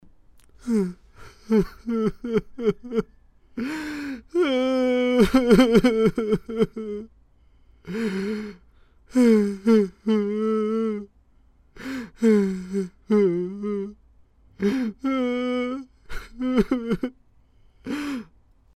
年长男声哭声音效免费音频素材下载